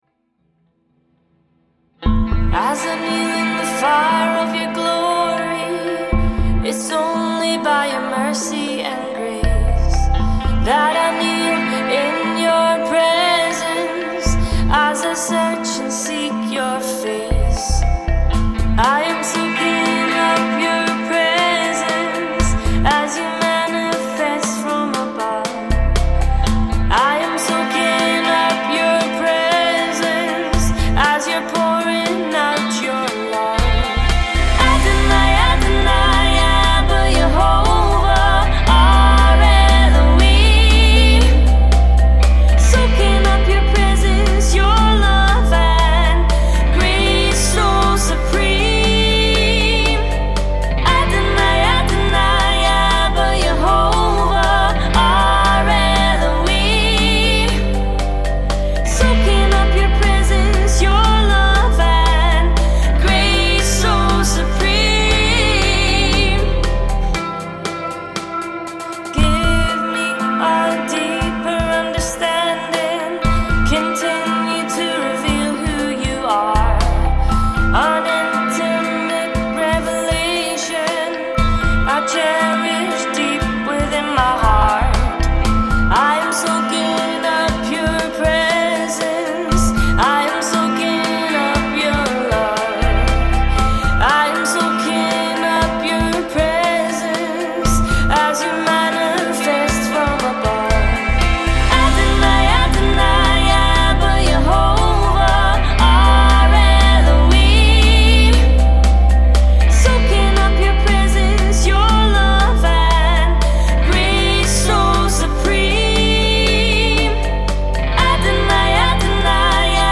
Lead & Background Vocals